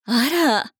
大人女性│女魔導師│リアクションボイス│商用利用可 フリーボイス素材 - freevoice4creators
喜ぶ